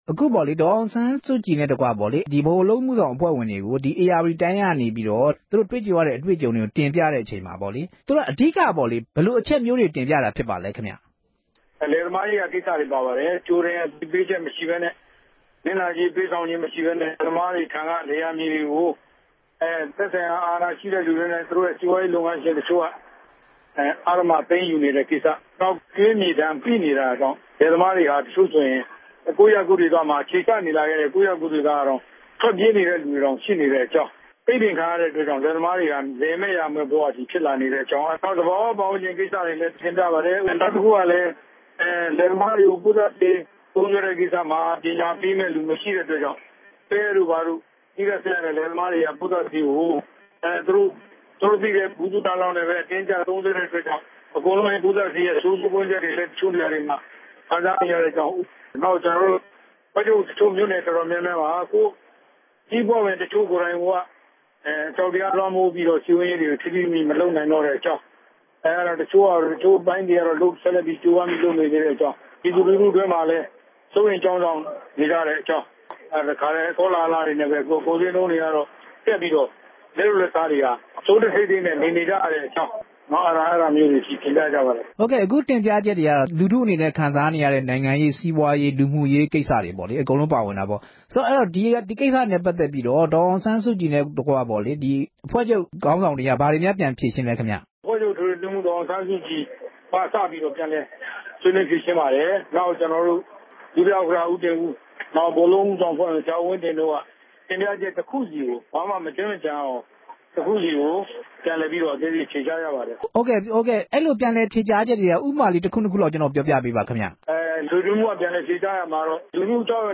ဒီကနေ့ ပြုလုပ်တဲ့ ပြည်နယ်တိုင်း စည်းရုံးရေးကော်မတီဝင်တွေနဲ့ တွေ့ဆုံပွဲမှာ NLD ပါတီရဲ့ အကြမ်းမဖက်နည်းနဲ့ တွေ့ဆုံဆွေးနွေးရေး ပေါ်လာအောင် ဆောင်ရွက်ချက်နဲ့ ပတ်သက်ပြီး နှစ်ပေါင်း ၂၀ ကျော်ခဲ့ပြီး ဖြစ်ပေမဲ့ ရလဒ် အပေါ် လူထုက အားမရ ဖြစ်နေတယ် ဆိုတဲ့ မေးခွန်းကို ဒေါ်အောင်ဆန်းစုကြည်က ခုလို ဖြေကြားခဲ့တာပါ။